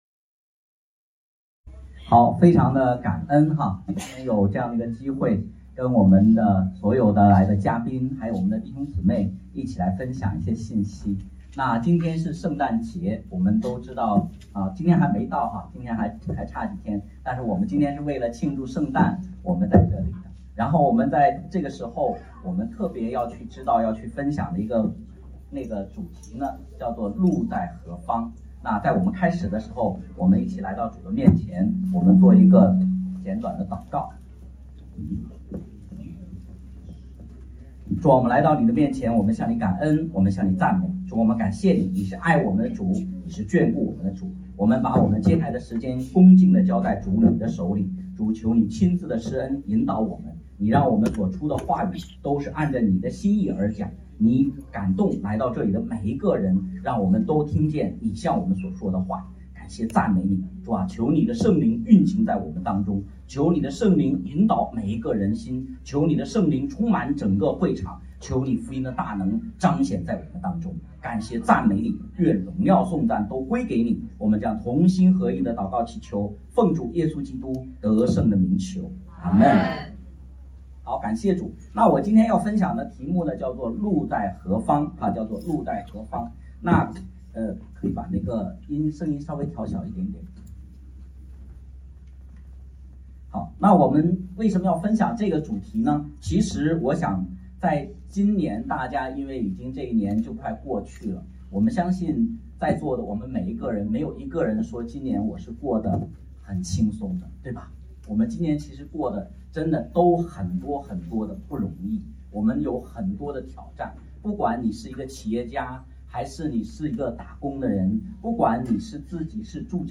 讲道录音 在正式直播看之前或您无法加入直播会议室的时候，您可以收听我们上一讲《 路在何方？ - 2024圣诞福音信息